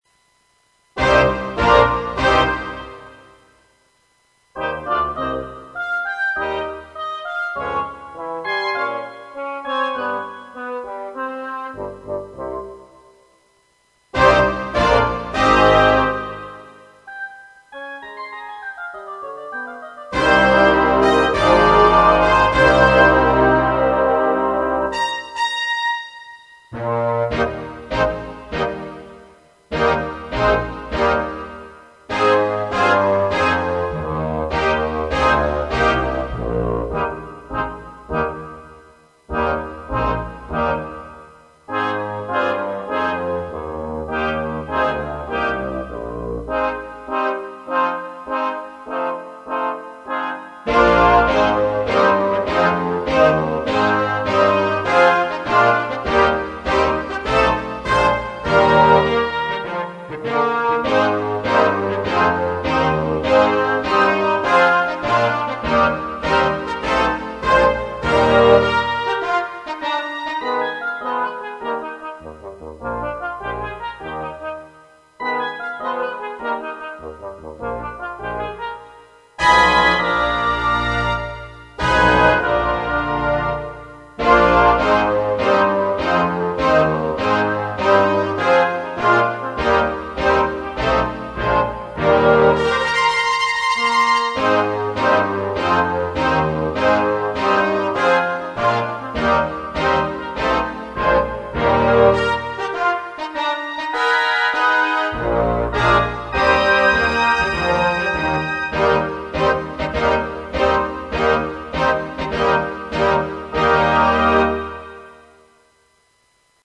Fanfare und Überleitung in das orchestrale Thema „If I´m not the one“
HERO tritt als wundertätiger Krisen - Manager auf und singt